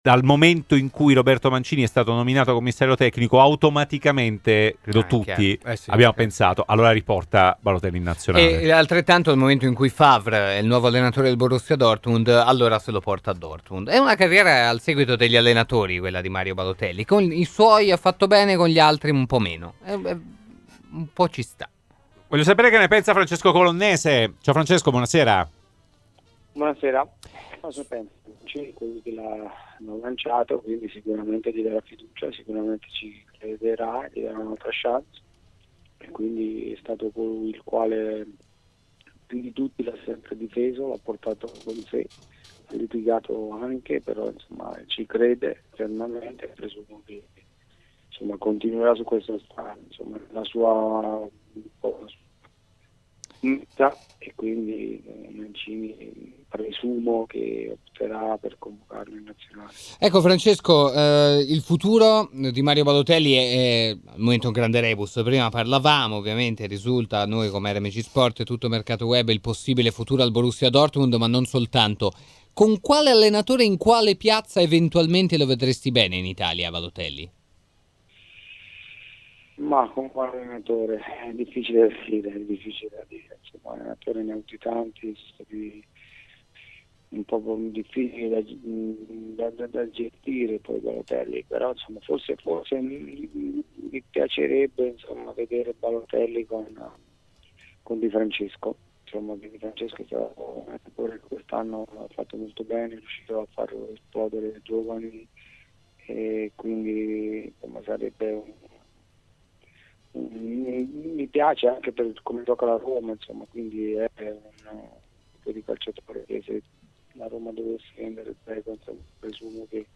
L'intervento di Francesco Colonnese, attualmente allenatore ma in passato ex giocatore di Inter, Lazio, Napoli e Roma, durante il Live Show di RMC Sport: